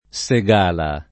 [ S e g# la ]